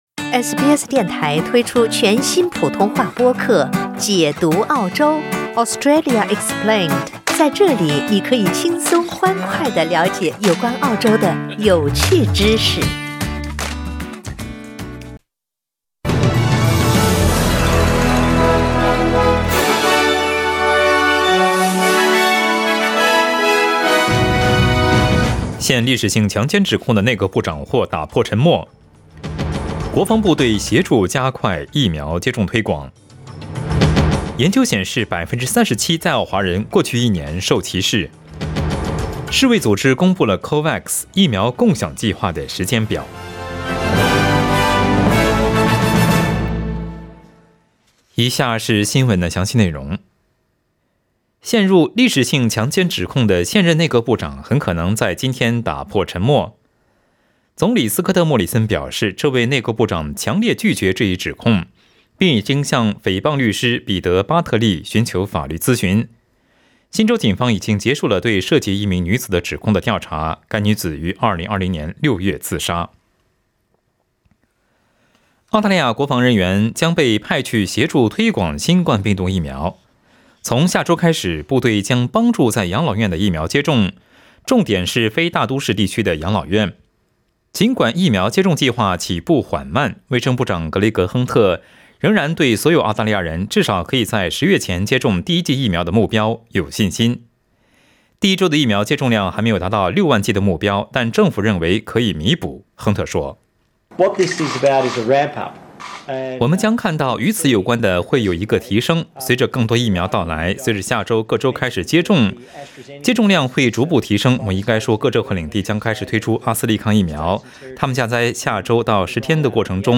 SBS早新聞 （3月3日）